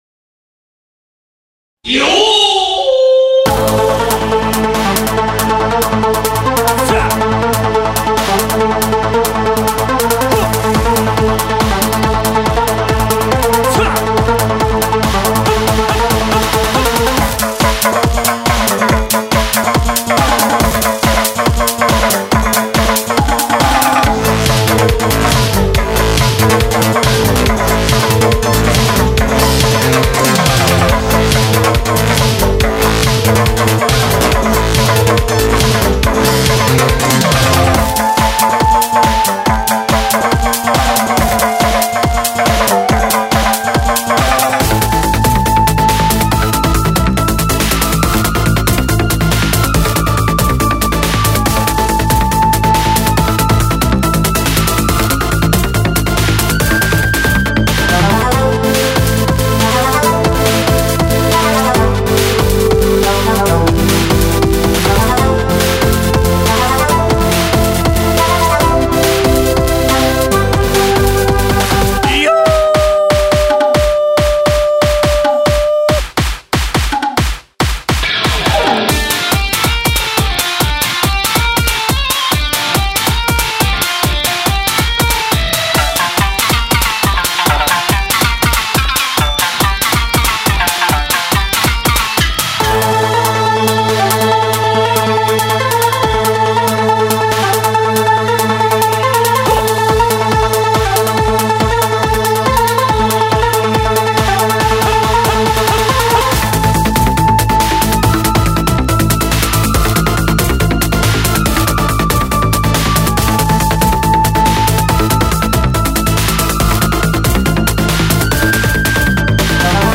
切なく、どこか懐かしさを感じさせる和風戦闘BGM
• 曲構成：イントロ → メインパート → ブレイク → ループ接続
• BPM：140（緩やかな中にリズムの揺らぎを含む）
• 音域設計：台詞や効果音の邪魔にならないよう中低域の配置に配慮
• ミックス面では空間系エフェクトを控えめにし、より“現場の臨場感”を重視しました。
🟢 無料ダウンロード（フリーBGM旧版/フェードアウト）